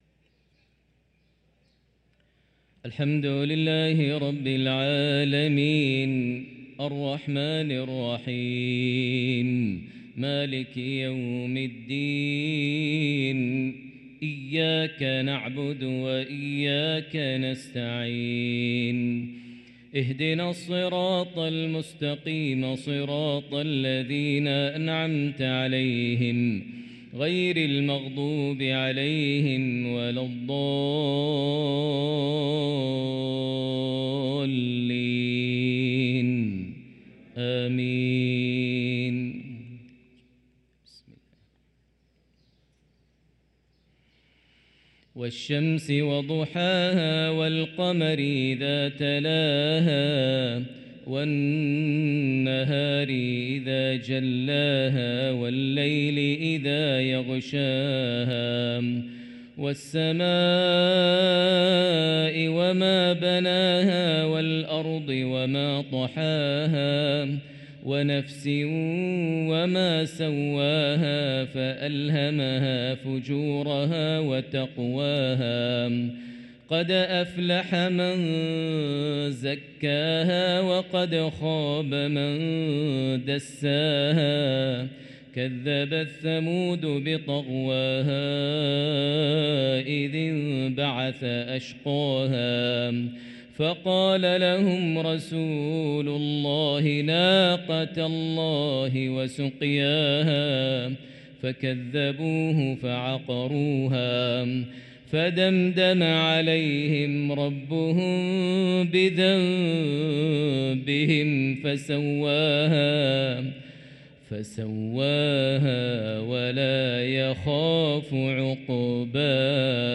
صلاة المغرب للقارئ ماهر المعيقلي 7 صفر 1445 هـ
تِلَاوَات الْحَرَمَيْن .